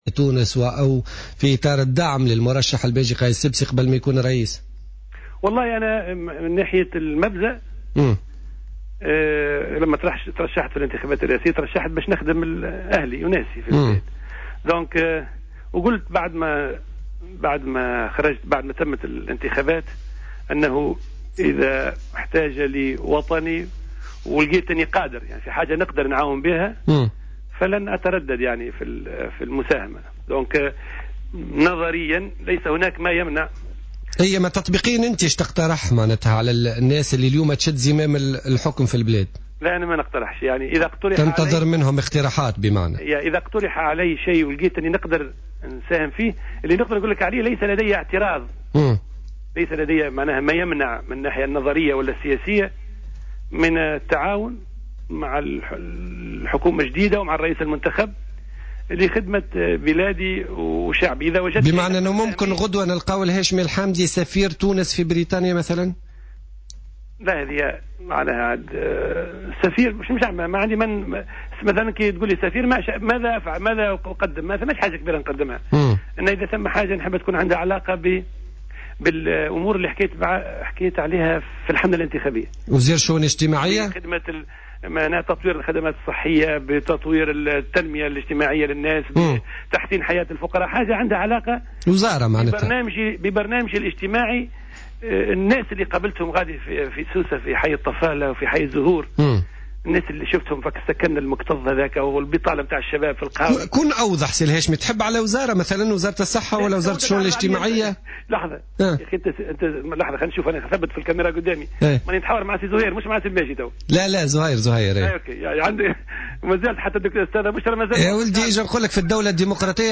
قال رئيس حزب تيار المحبة الهاشمي الحامدي في برنامج "بوليتيكا" اليوم الجمعة إنه لا مانع من المشاركة في الحكومة الجديدة والتعاون معها.